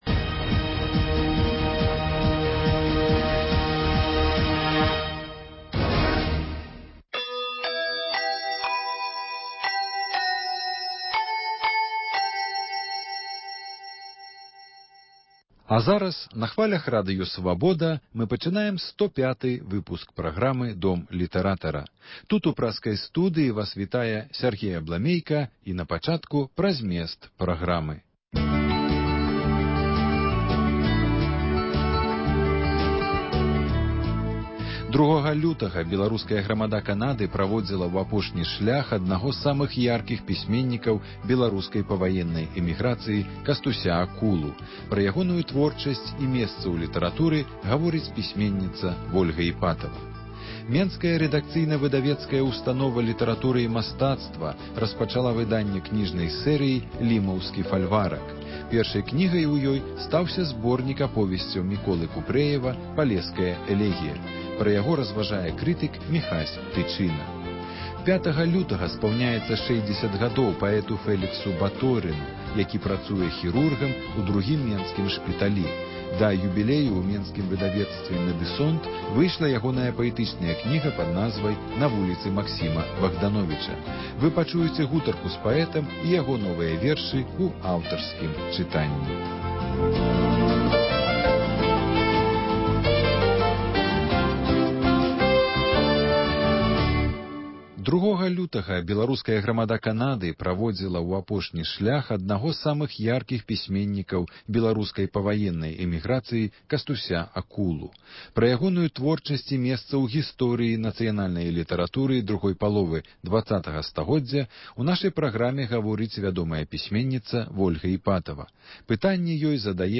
гутарка
інтэрвію з паэтам